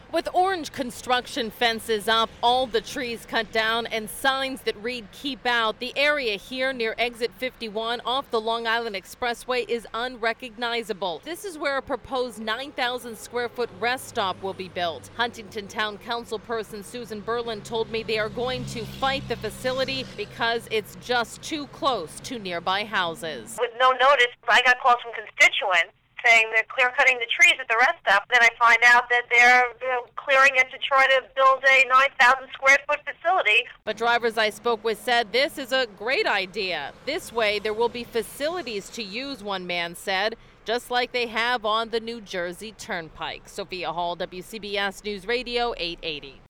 Listen below to Susan Berland’s CBSRadio 880 segment: